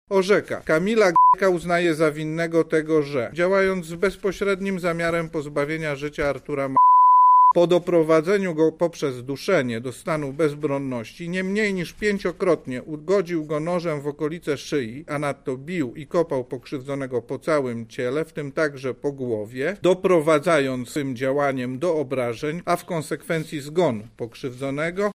mówi sędzia Andrzej Wach z Sądu Okręgowego w Lublinie.